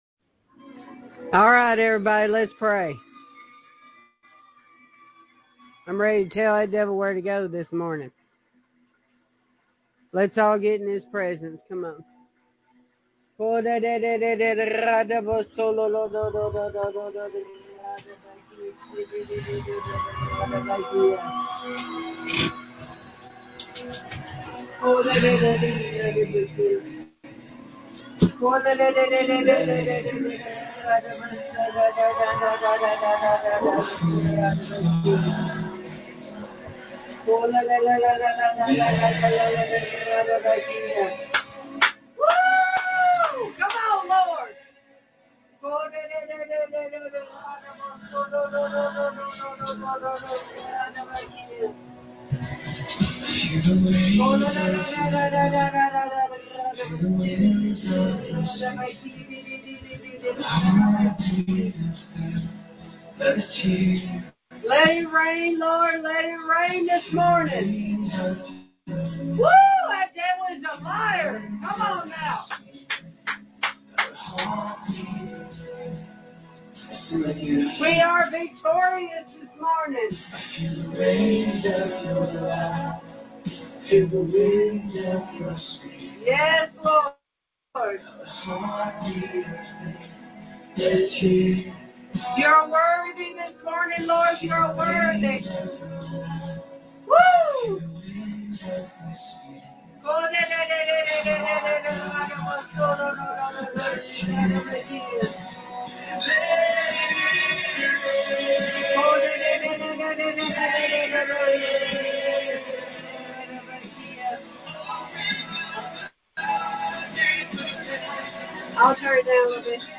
I woke up this morning with Rev. 14 and Psalm 32 on my mind. I read the chapters and told many stories. We prayed.